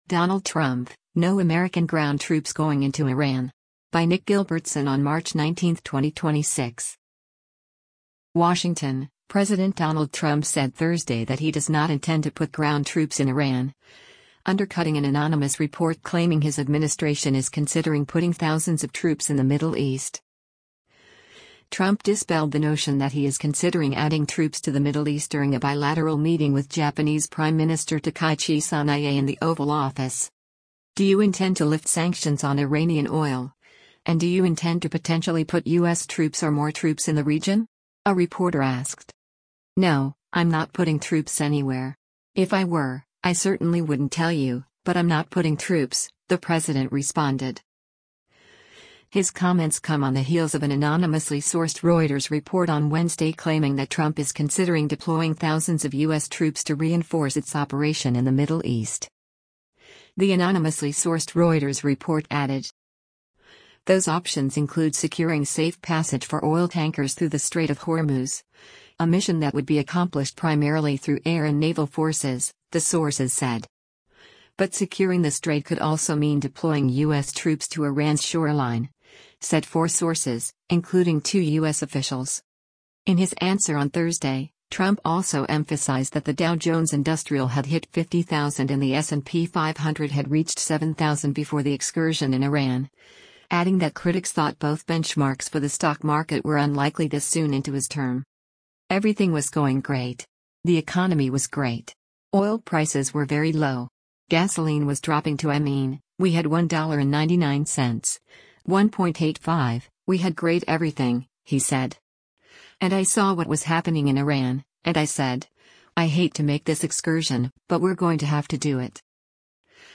Trump dispelled the notion that he is considering adding troops to the Middle East during a bilateral meeting with Japanese Prime Minister Takaichi Sanae in the Oval Office.
“Do you intend to lift sanctions on Iranian oil, and do you intend to potentially put U.S. troops or more troops in the region?” a reporter asked.